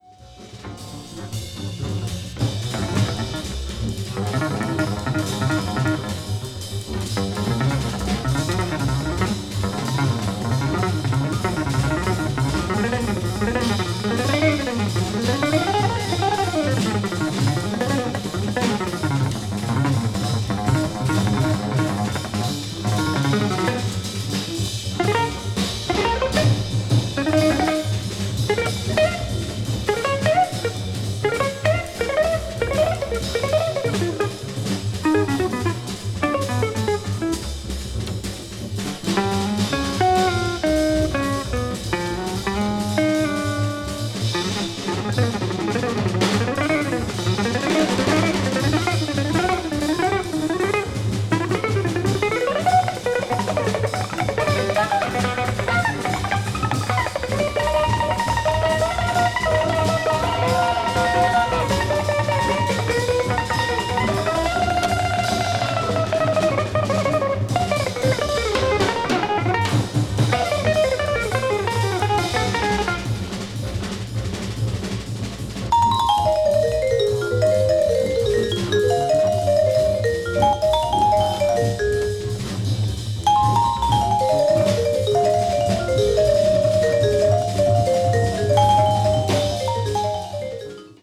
media : EX+/EX+(some slightly noises.)
bass
guitar